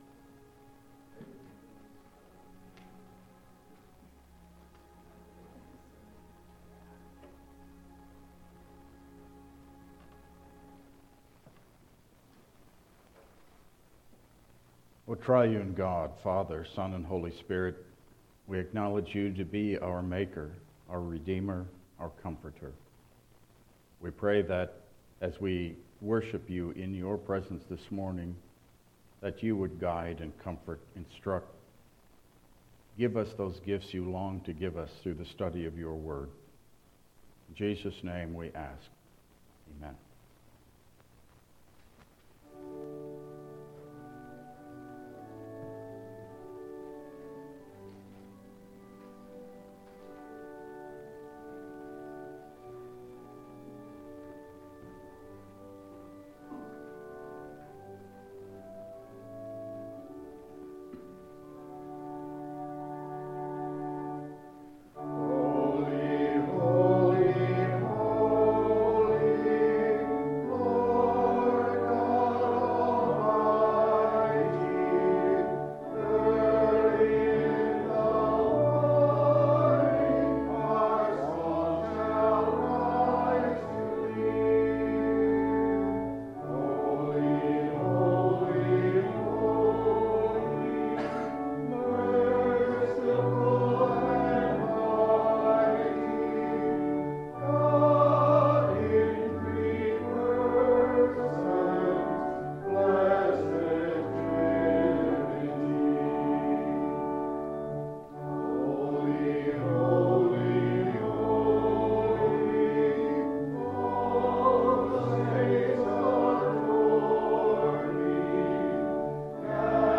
Download Files Printed Sermon and Bulletin
Passage: Ephesians 1:3-14 Service Type: Regular Service